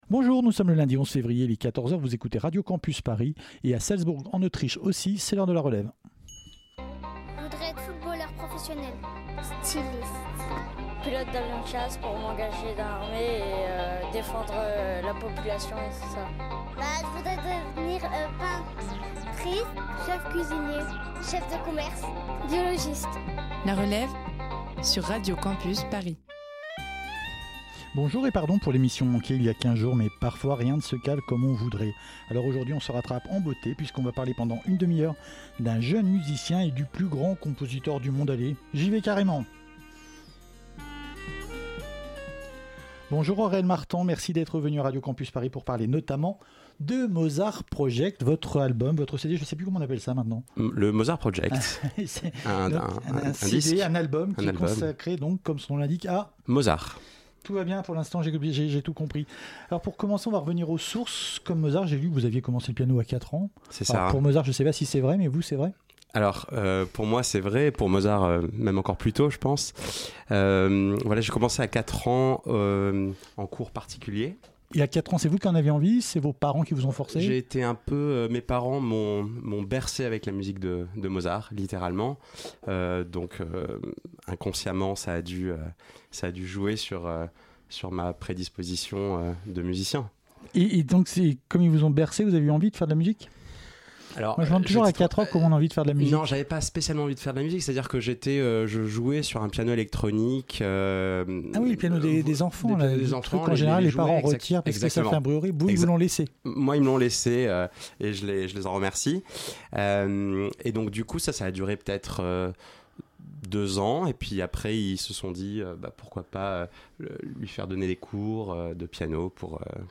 Type Entretien